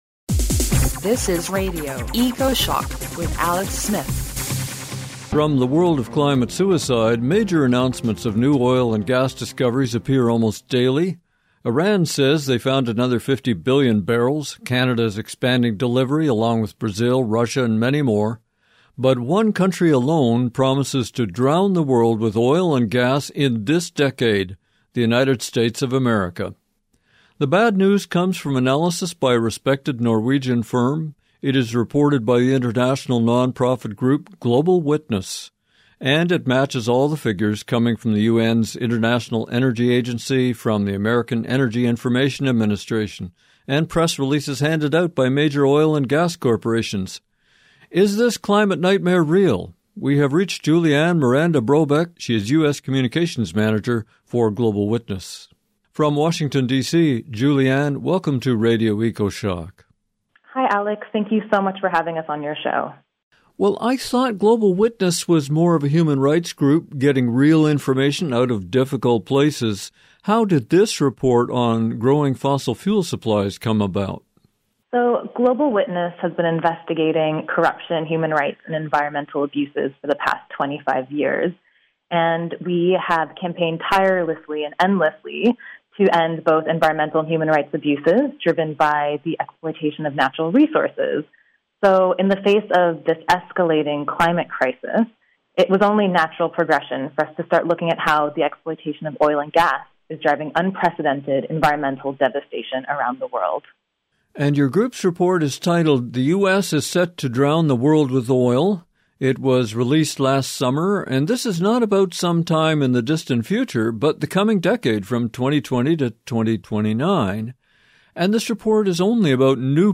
27 minute interview